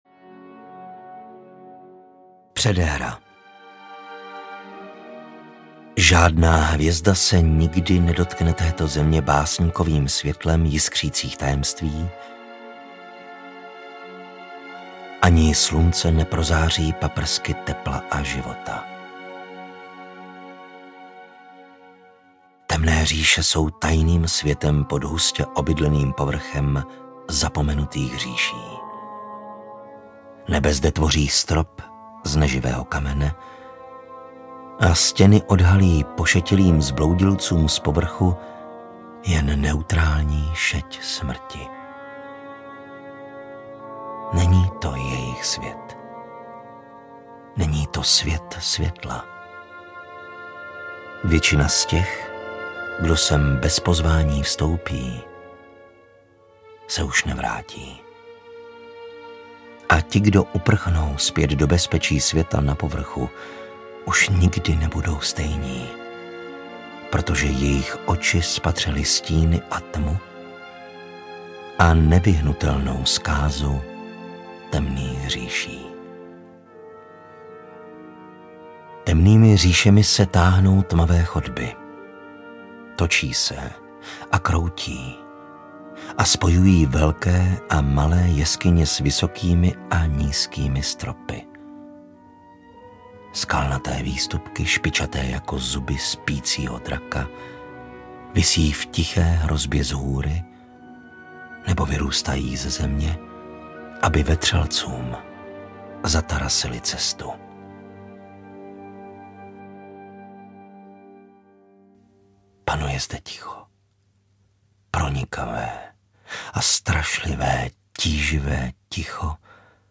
Temný elf 1: Domovina audiokniha
Ukázka z knihy